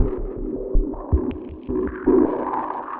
Index of /musicradar/rhythmic-inspiration-samples/80bpm